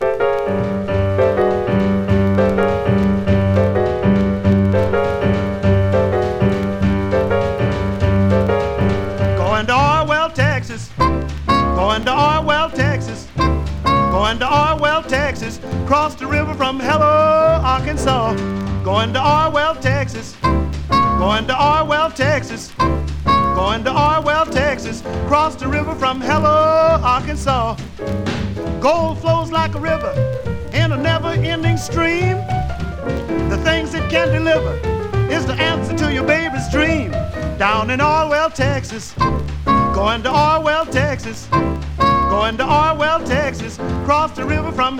黒光りするような魅力溢れる演奏、素敵さと洒落た印象も含んだ好内容。
Jazz, Rhythm & Blues　USA　12inchレコード　33rpm　Mono